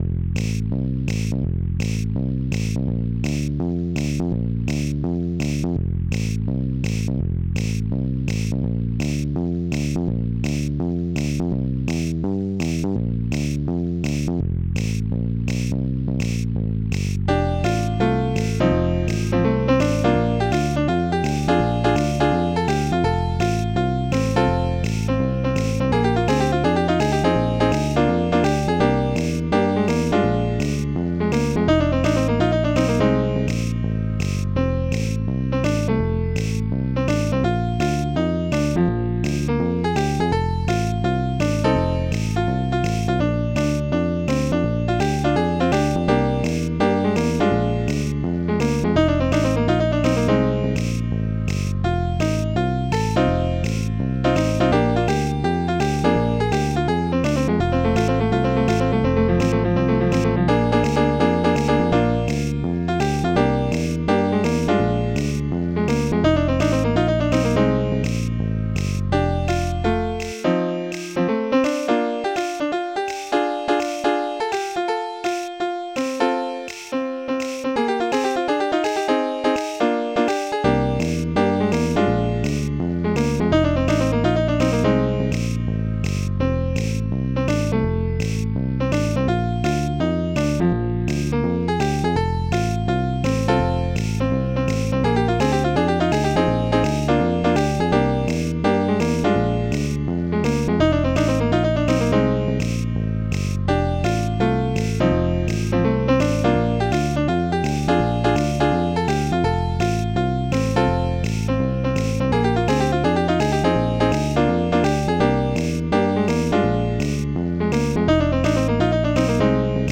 Blues.mp3